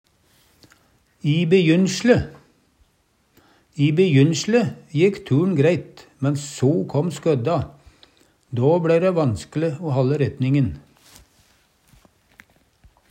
i bejynsle - Numedalsmål (en-US)